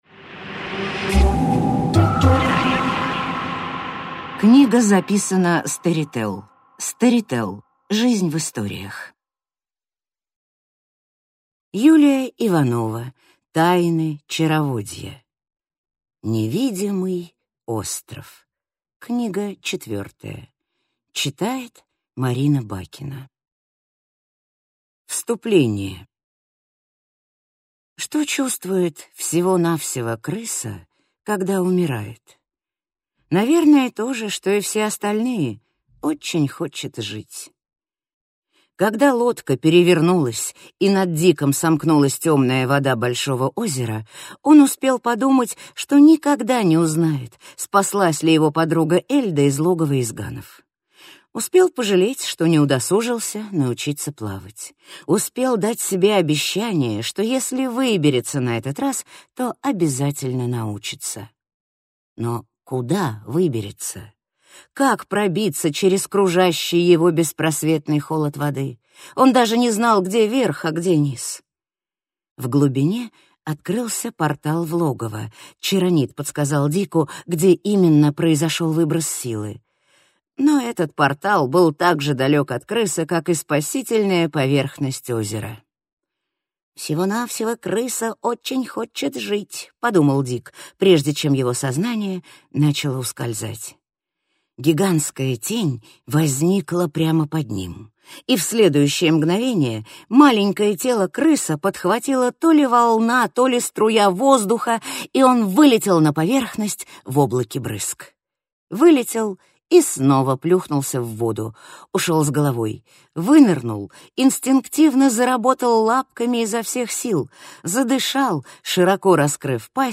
Аудиокнига Тайны Чароводья. Невидимый остров. Книга четвёртая | Библиотека аудиокниг